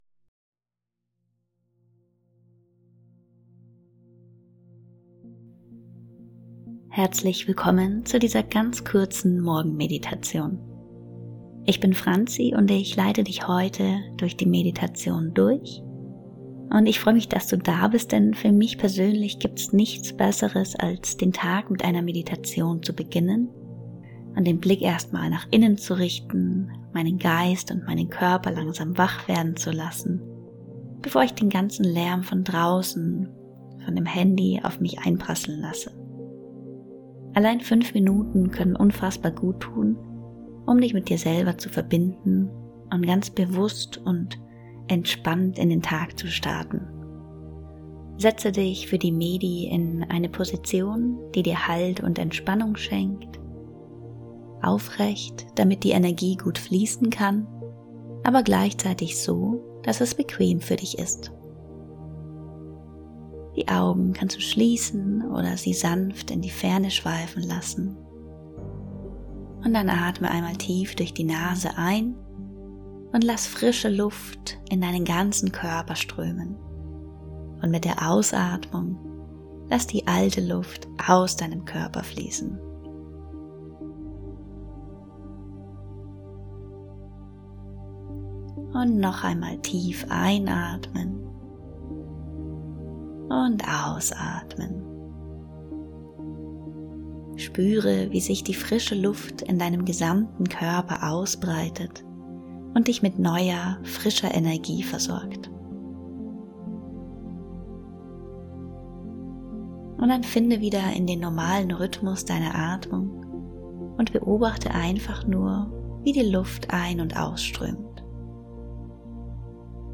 Beginne deinen Morgen mit einem Moment der Achtsamkeit und bringe Körper und Geist sanft in den neuen Tag. In dieser kurzen Meditation richtest du deinen Blick nach innen, atmest bewusst und füllst dich mit neuer Energie. Mit kleinen Dankbarkeits-Impulsen lenkst du deine Aufmerksamkeit auf das Positive und startest voller Klarheit und Motivation in den Tag.